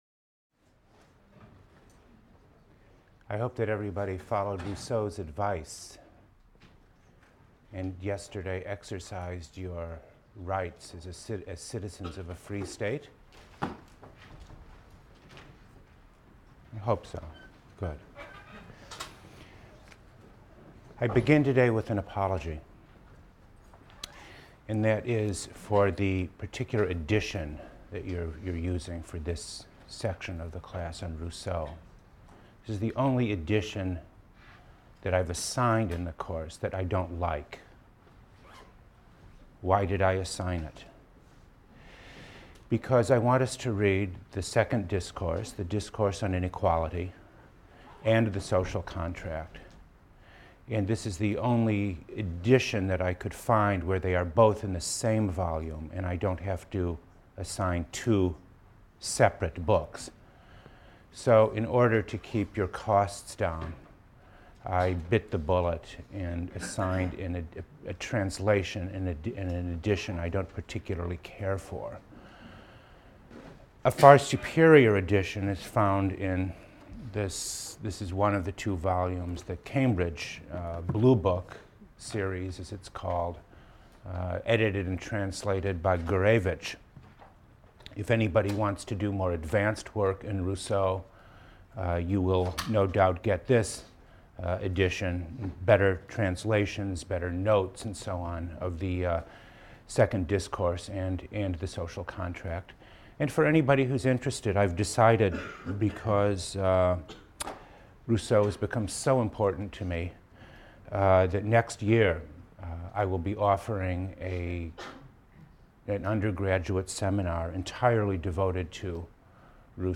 PLSC 114 - Lecture 18 - Democracy and Participation: Rousseau, Discourse on Inequality (Author’s Preface, Part I) | Open Yale Courses